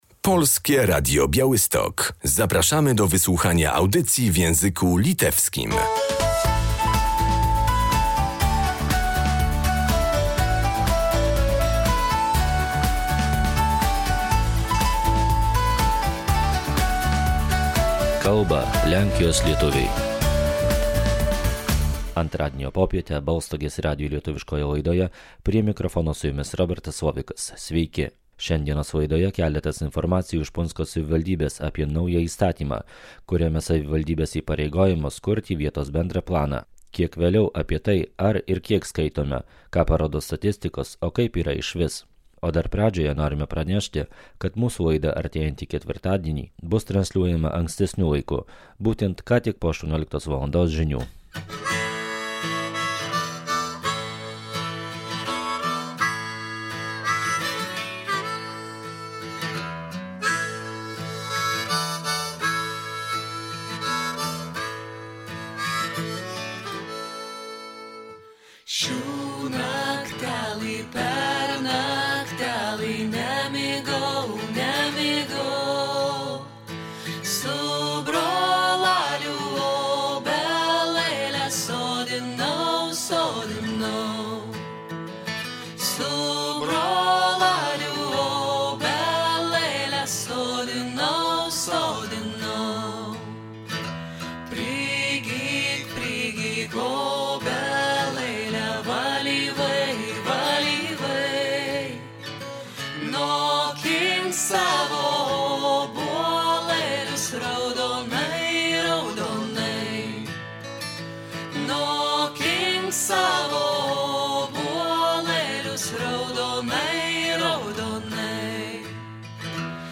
Czym jest Plan Ogólny Gminy Puńsk i jakie zmiany niesie w porównaniu do dotychczas obowiązującego miejscowego planu zagospodarowania przestrzennego. O obowiązkach, z nim związanych, dla samorządów i mieszkańców opowiada wójt gminy Puńsk Witold Liszkowski.